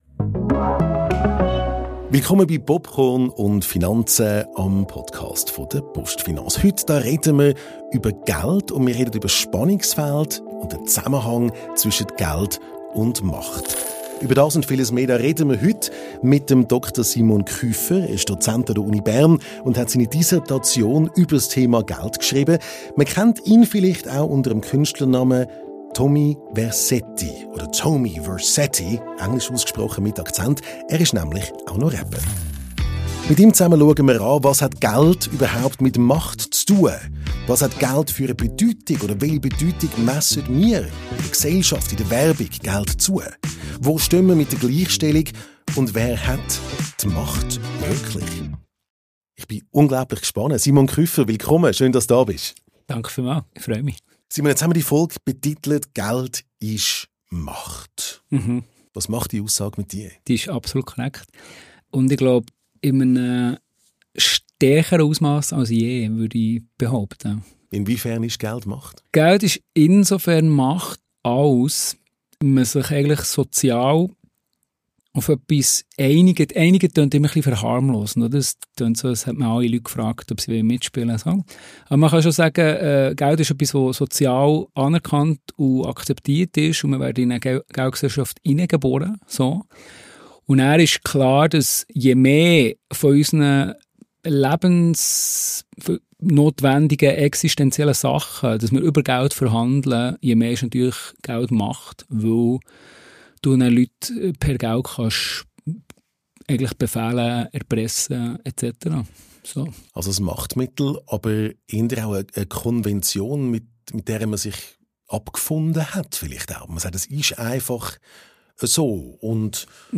Ein persönliches, kritisches Gespräch über Geld, Demokratie und unsere Beziehung zu Macht.